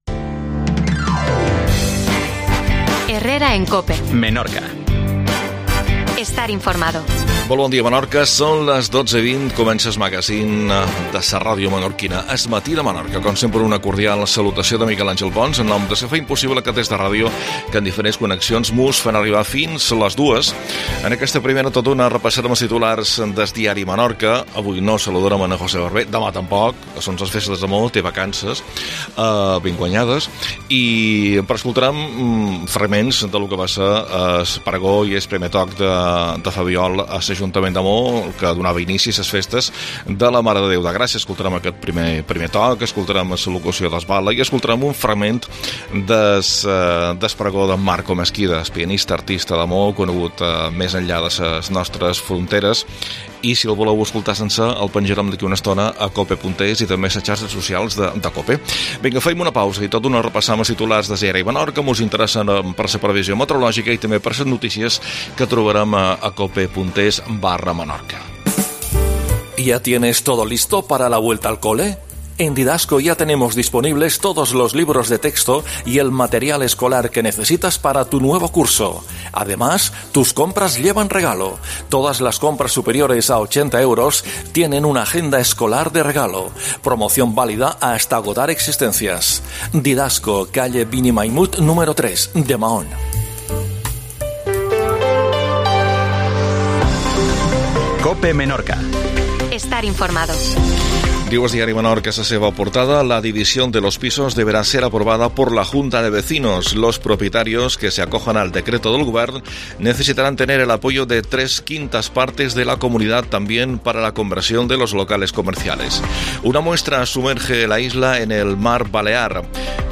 Primer toc de fabiol de les festes de la Mare de deu de Gracia